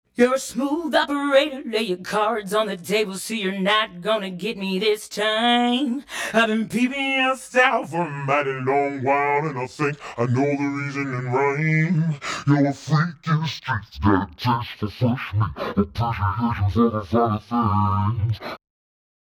Chromatic Shift is an audio plugin (AU and VST3) that uses three interpolating delays to pitch shift audio. The range of the plugin is two octaves below and two octaves above the original audio.